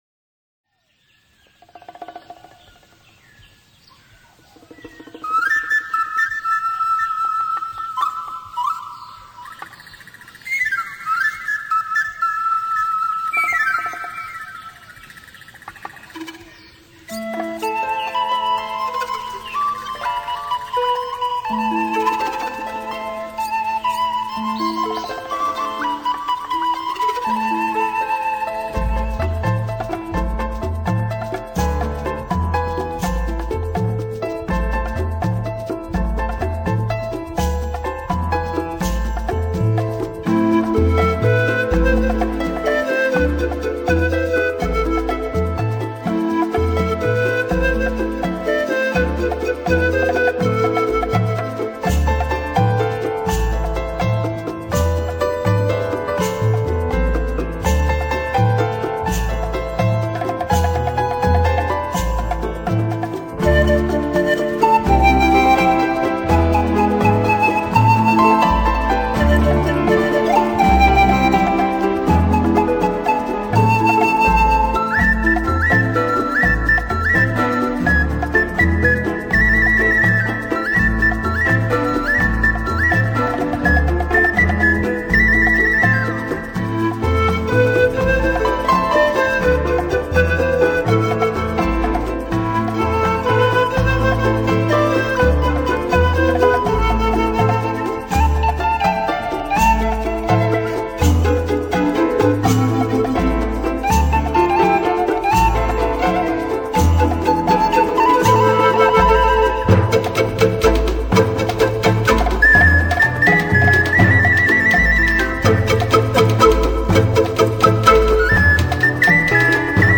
钢琴与排笛的精彩乐器对话，所营造的感觉非常缥缈浪漫，轻盈，没
有烦人的音效，带你进入自在的飞翔之旅，经历最无拘无束的感受。
演绎一首首旋律优美动听、令人心旷神怡的乐曲。